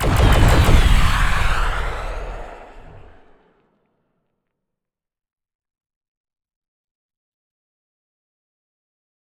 CosmicRageSounds / ogg / ships / combat / weapons / salvomisa.ogg